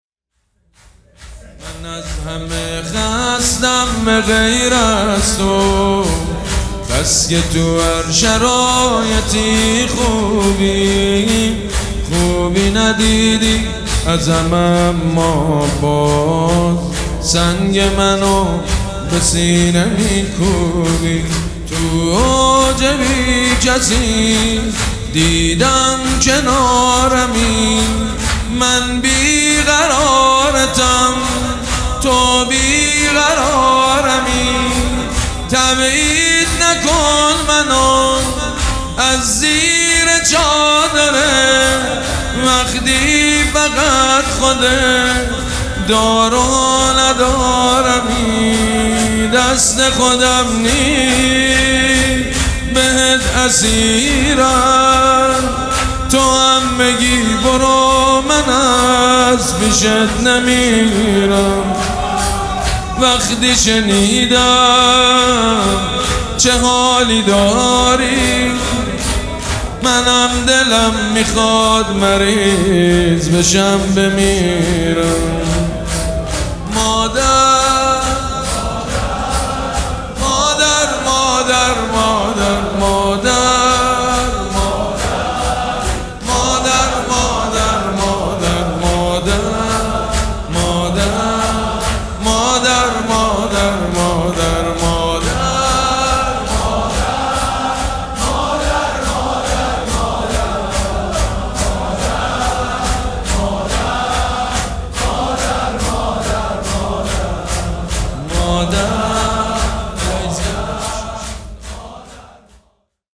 گلچین مداحی به مناسبت شهادت حضرت زهرا(س)